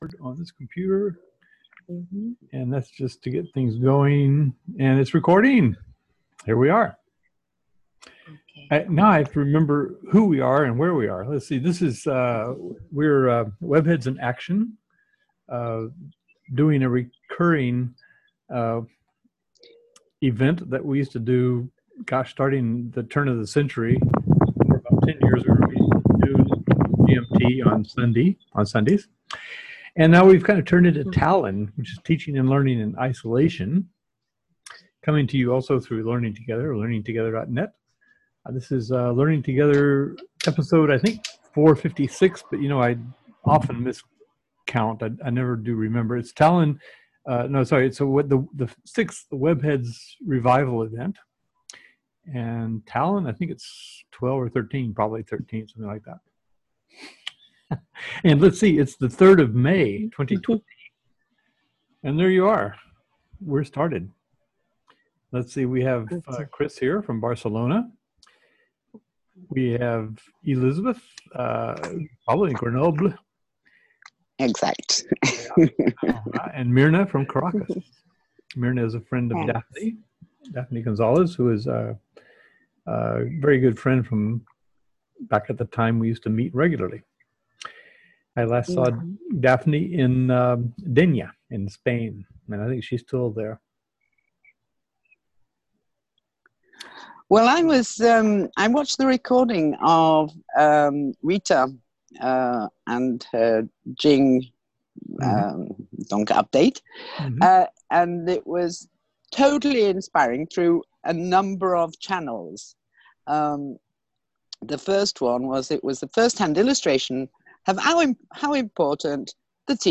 Where? in the TALIN2020 Zoom room